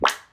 clap2.mp3